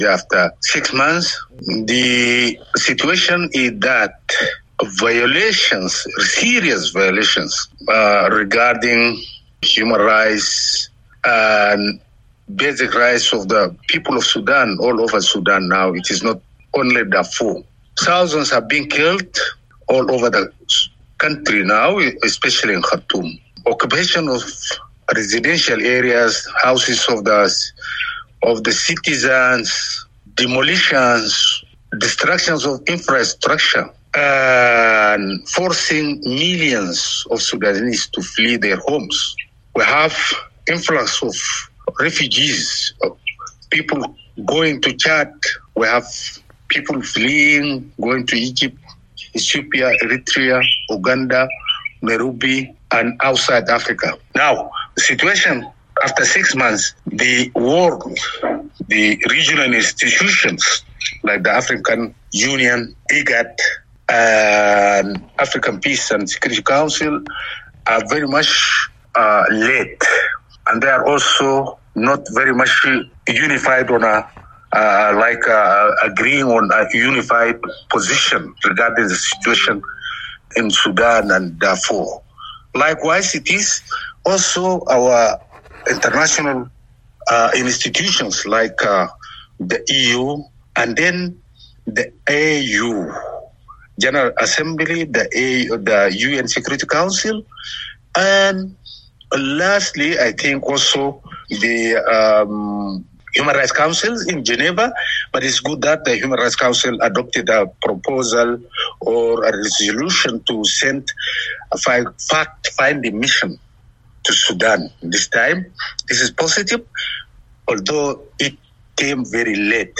Analysis: Challenges in Resolving Sudan's Conflict [4:25]